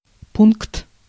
Ääntäminen
France (Paris): IPA: [æ̃ pwæ̃]